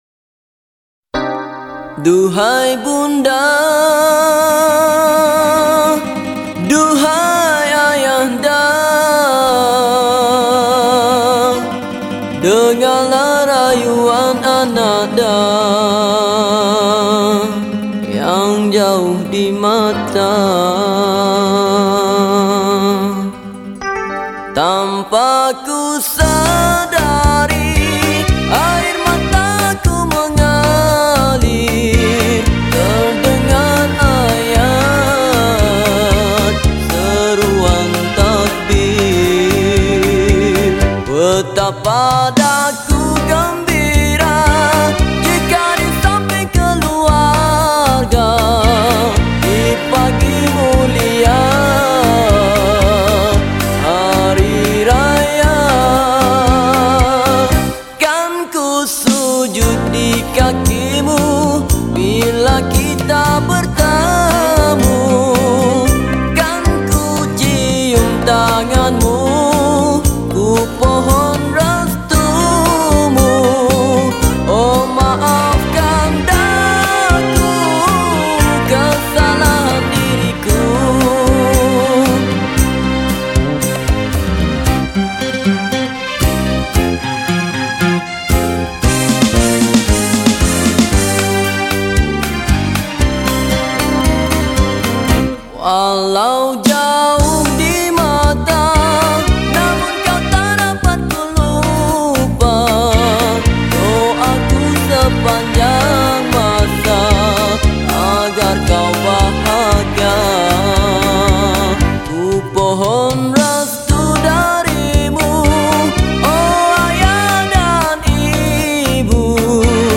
Genre: Raya.